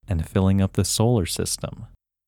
WHOLENESS English Male 12
WHOLENESS-English-Male-12.mp3